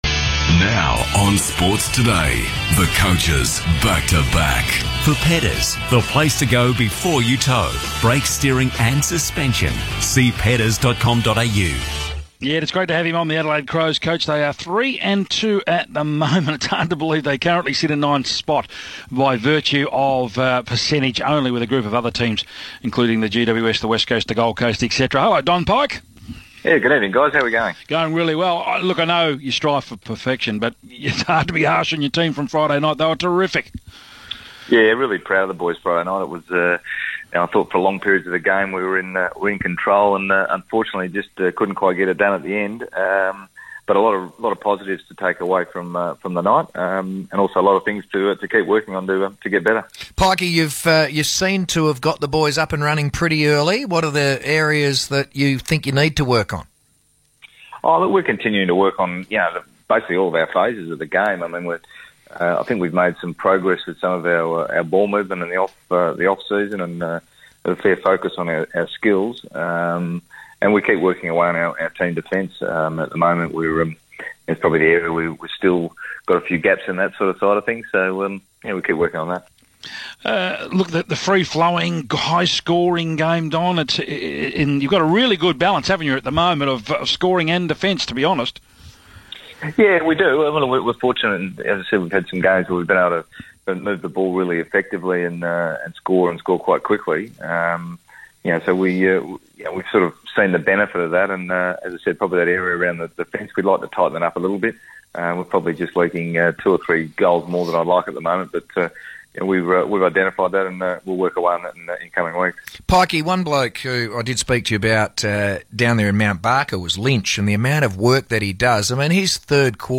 Crows Senior Coach Don Pyke spoke on Perth radio station 6PR after Adelaide's three-point loss to the Hawks